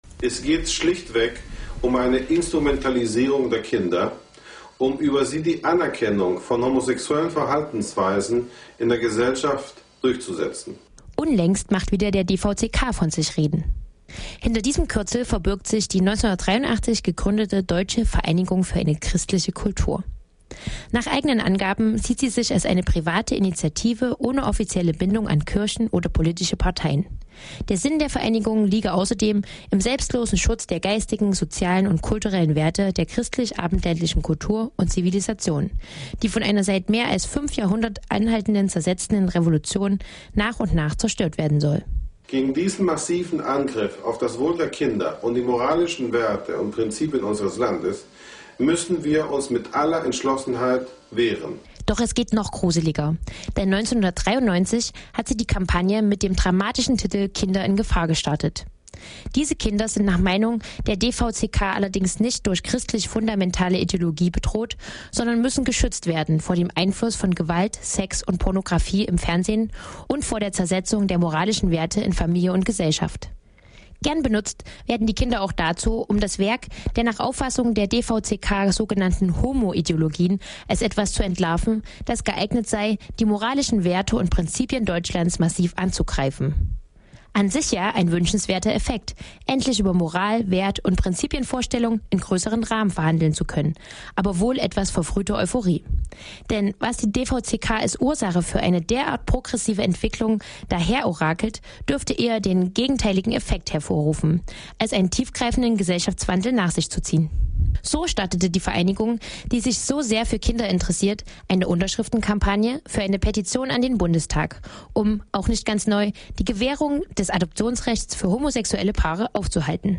In der Sendung vom 19. Februar direkt nach der Berlinale hatten wir in unserer Sendung ein Gespräch über das Filmfestival und den queeren Preis, den Teddy Award.